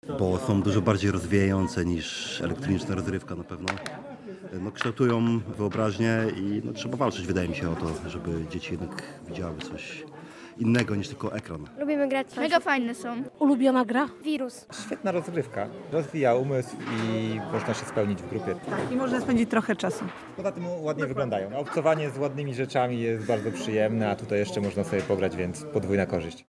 W mieście 26 i 27 kwietnia trwa El Festiwal Planszówkowy Zawrót Głowy.
Co sprawia, że planszówki wciąż są atrakcyjne? Na to pytanie odpowiedzieli gracze.